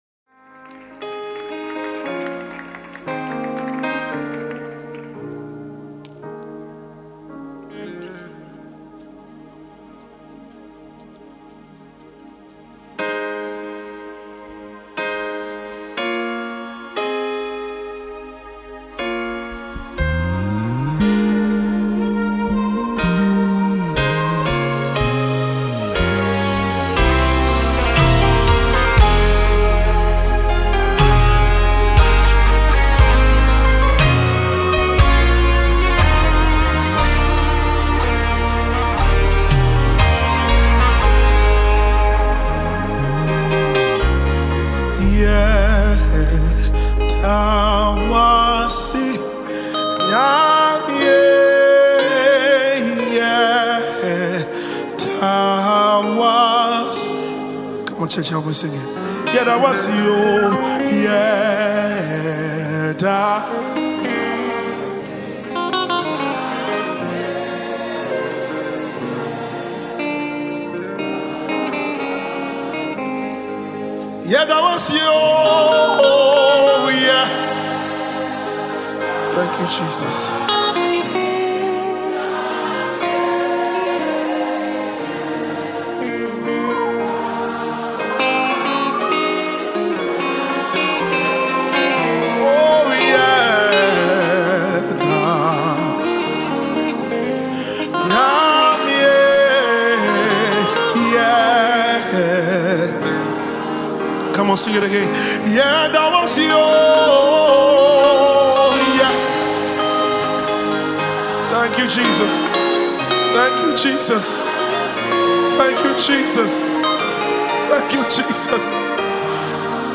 Ghanaian gospel musician
soul-stirring new single